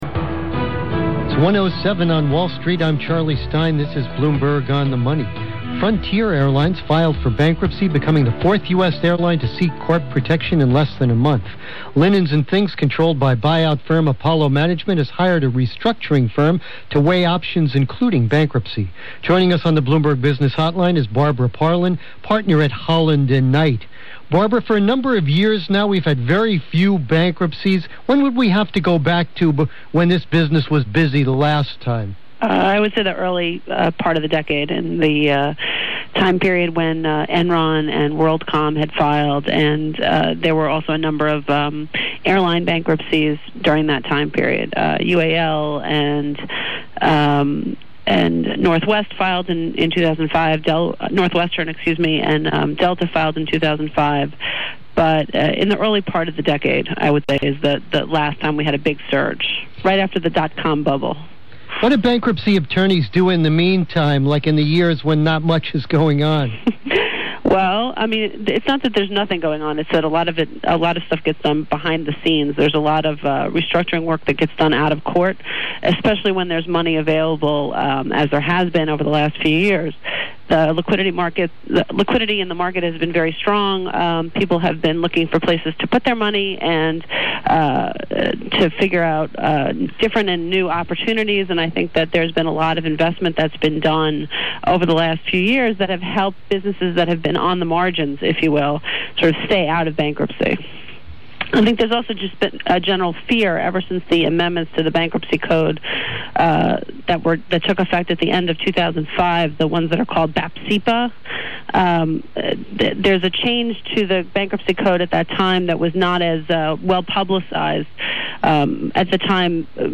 Posted with permission from Bloomberg Radio, interview